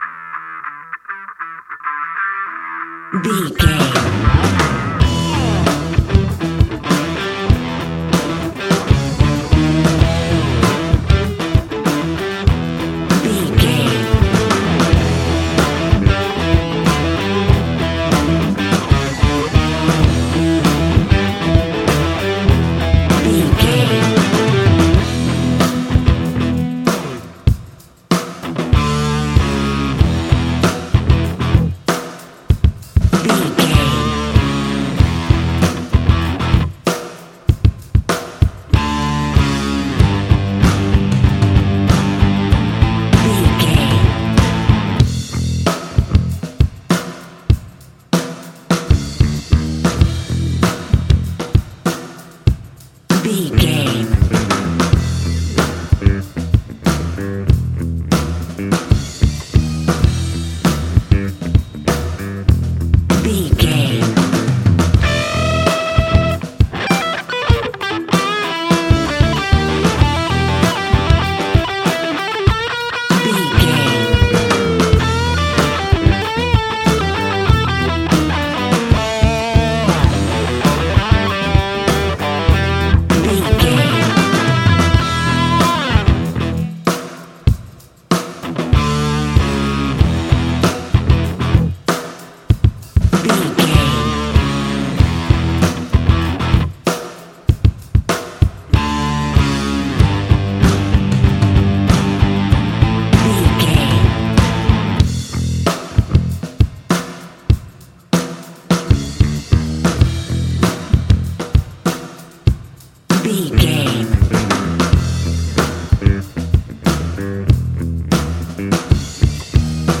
Ionian/Major
E♭
hard rock
heavy rock
distortion
instrumentals